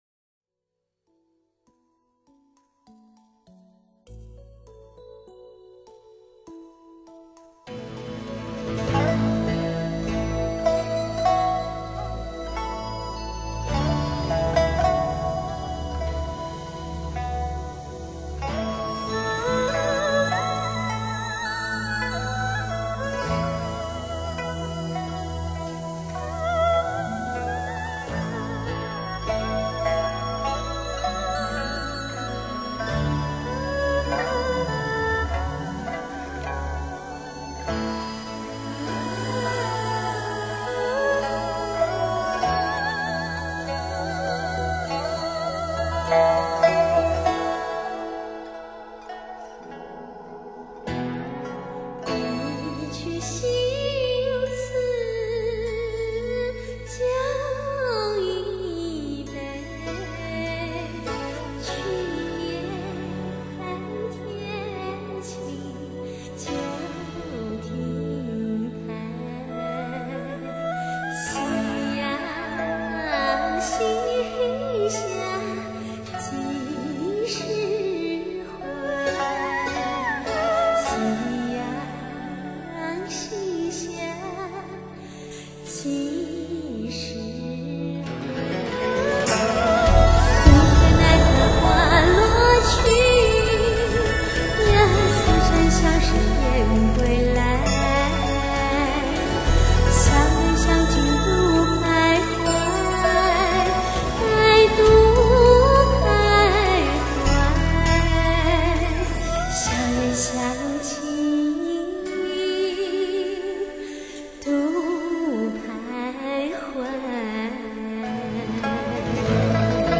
现代音乐演绎古人情怀